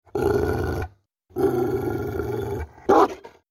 Dogs Growling Fx 3 Téléchargement d'Effet Sonore